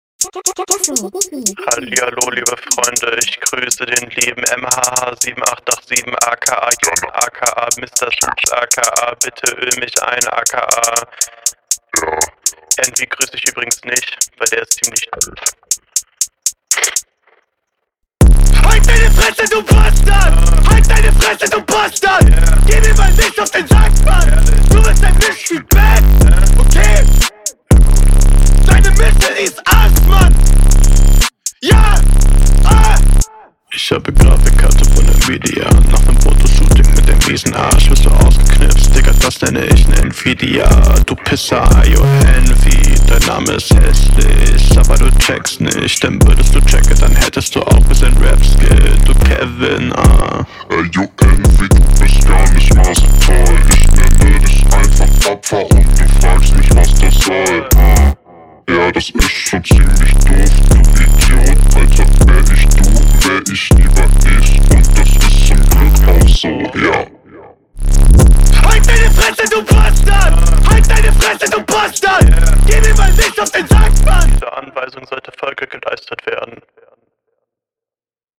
glaube du hättest auf dem Beat mit dem Styl was reißen können.
ist nicht anhörbar, ist in allen battlerap aspekten beschissen. das einzige was du schaffst, ist …